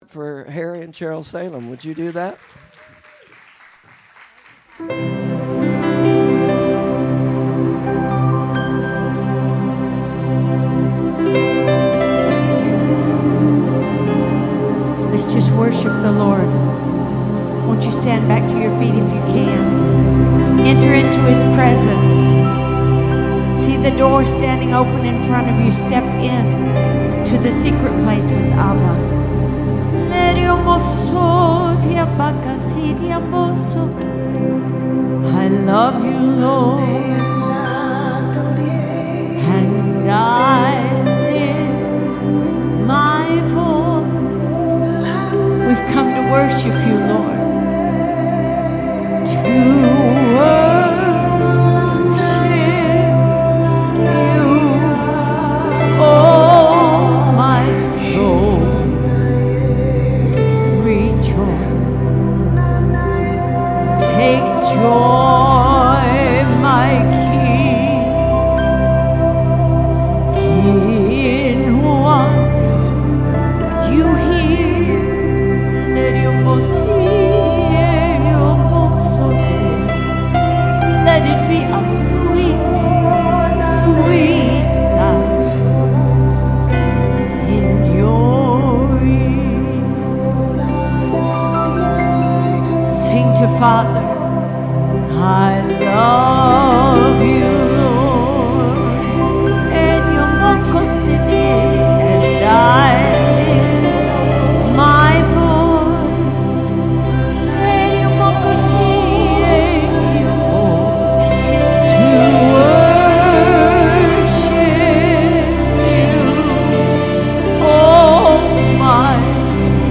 PM Service